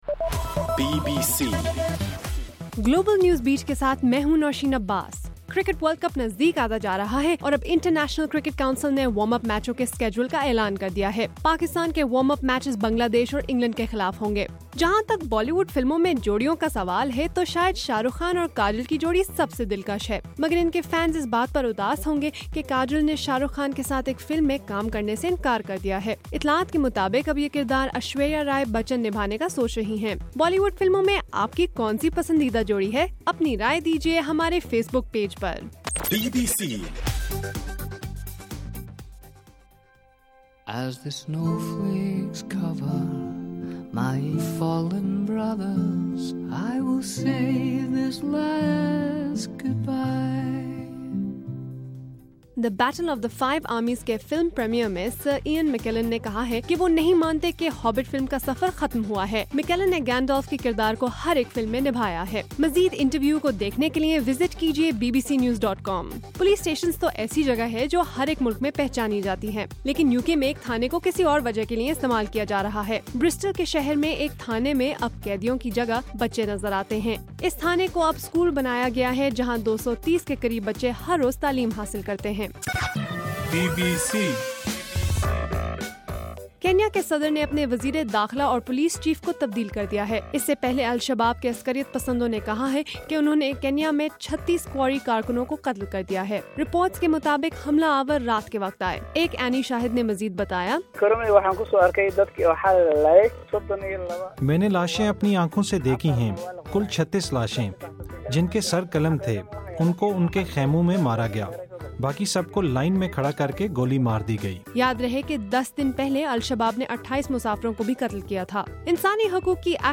دسمبر 2: رات 10 بجے کا گلوبل نیوز بیٹ بُلیٹن